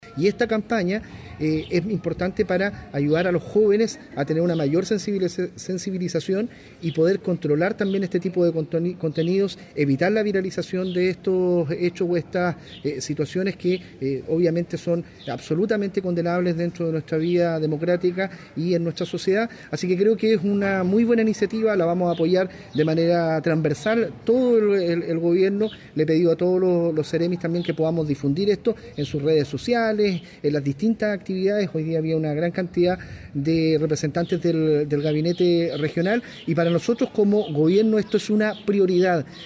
La iniciativa, que fue presentada en el Liceo Polivalente Experimental de Concepción, busca generar consciencia en los jóvenes sobre la violencia de género e informar de los respectivos mecanismos de denuncia.
El delegado presidencial del Biobío, Eduardo Pacheco, valoró la iniciativa y manifestó su apoyo, señalando que le solicitó a todos los seremis ayudar en su divulgación.